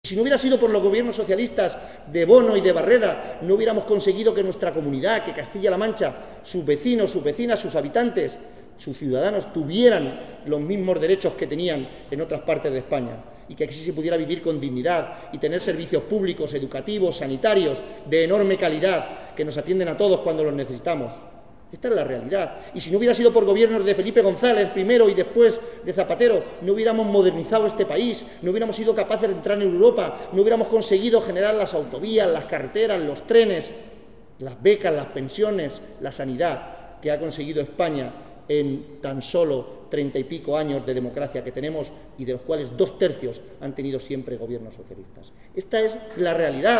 En su visita a Chillón y a Malagón
Cortes de audio de la rueda de prensa